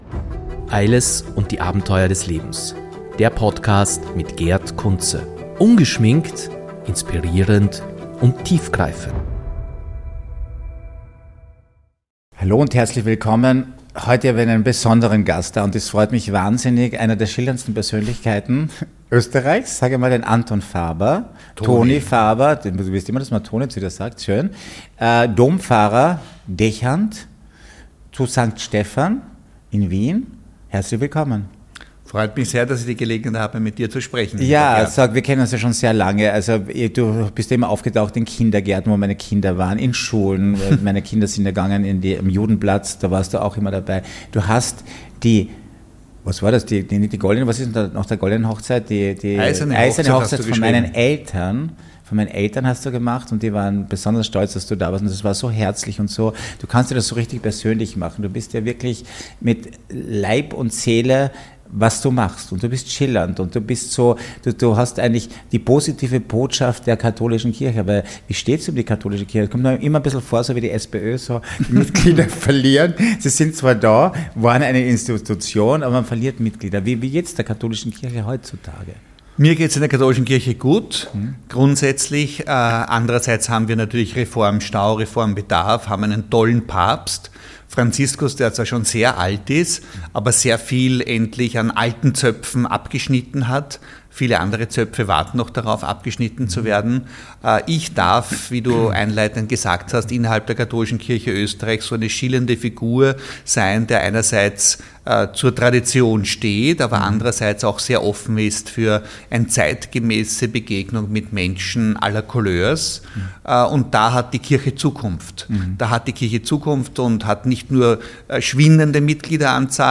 Ein offenes, ehrliches und tiefgründiges Gespräch erwartet euch.